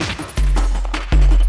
53LOOP02SD-L.wav